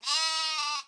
Schaf2_ORIG-1503.wav